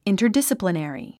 発音 ìntərdísəplineri インタァディスポネァリィ